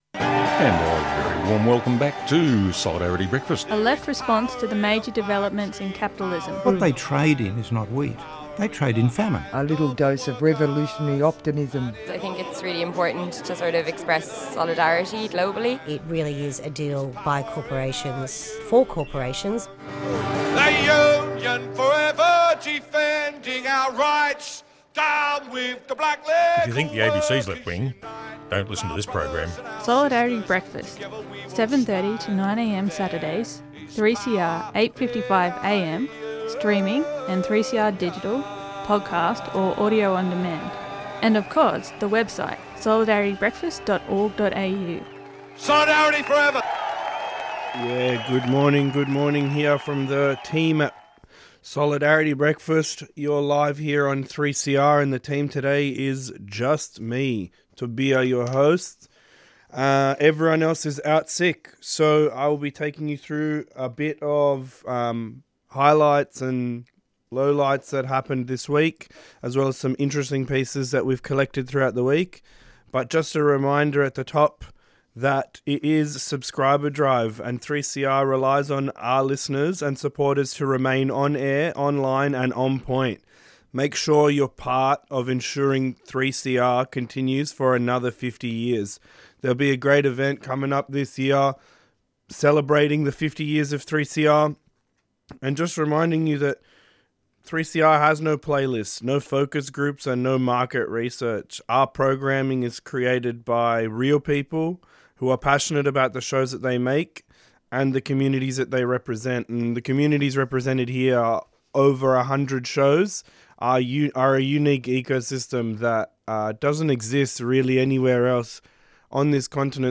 Solidarity Breakfast covers industrial current affairs and issues from a rank and file unionist perspective, with a focus on the broad labour movement and democratic struggle - both locally & internationally. Discussion and interviews with experts on bigger picture matters; global capitalism/worker